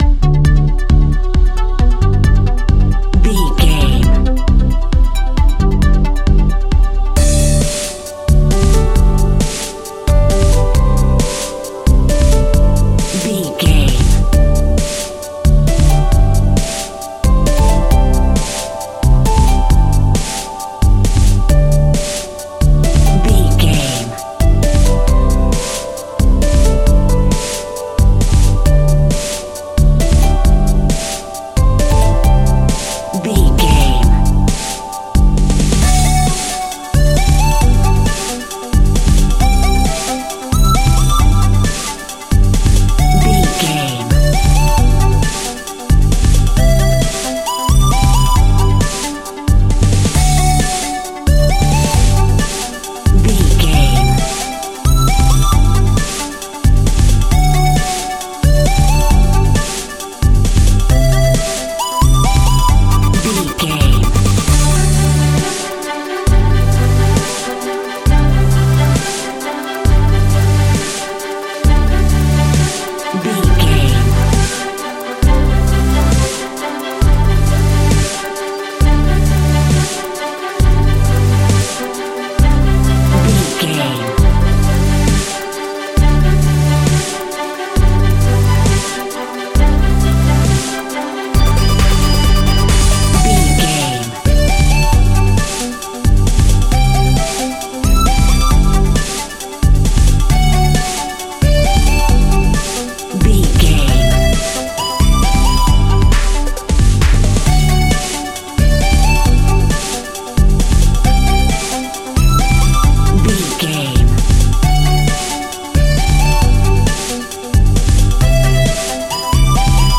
Ionian/Major
D